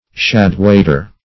Search Result for " shad-waiter" : The Collaborative International Dictionary of English v.0.48: Shad-waiter \Shad"-wait`er\, n. (Zool.) A lake whitefish; the roundfish.